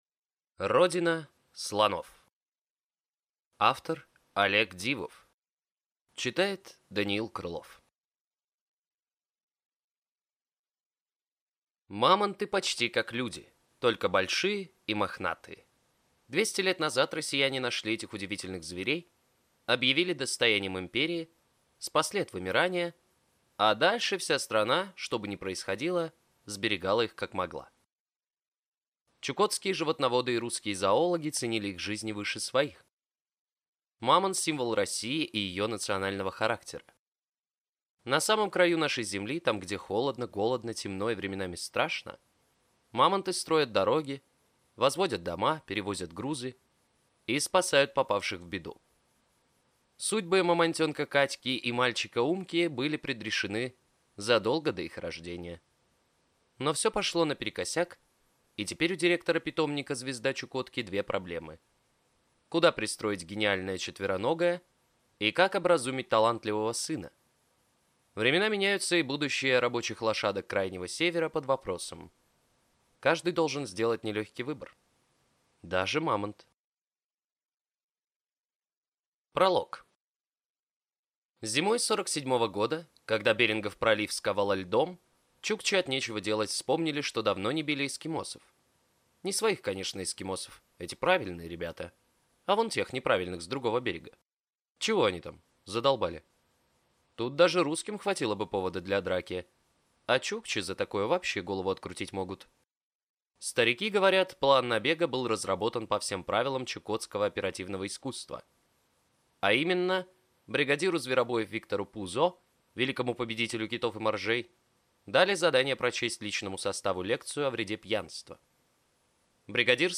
Аудиокнига Родина слонов | Библиотека аудиокниг